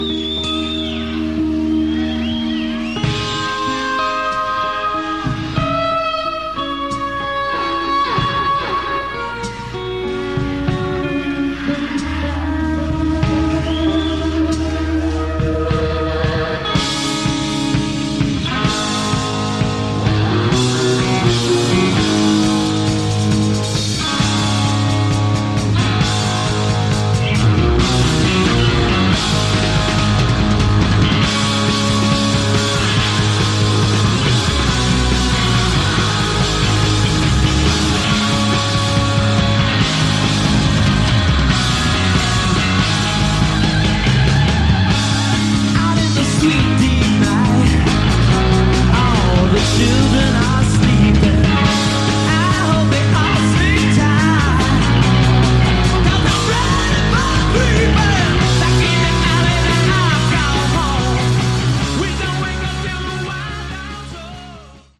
Category: Hard Rock
lead vocals
guitar
bass